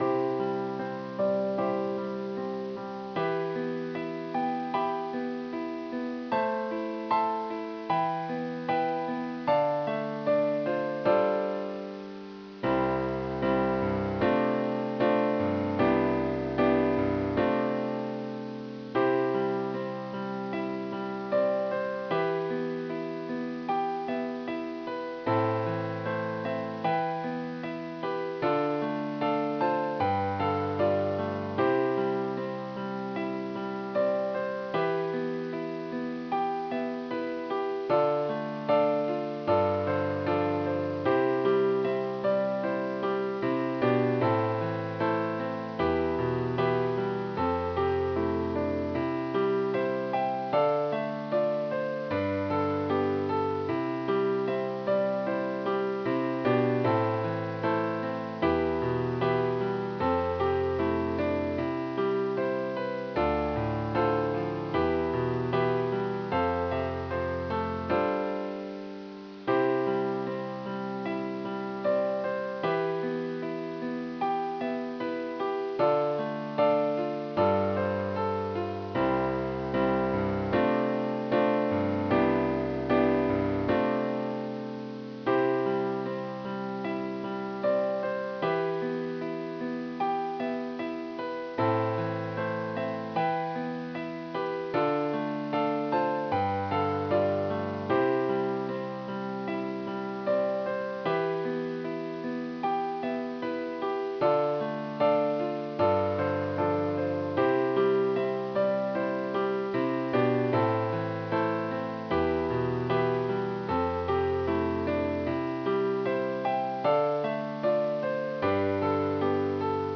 ピアノ（斉唱版）(4MB)(wav type)
※１～６は音声を圧縮しています。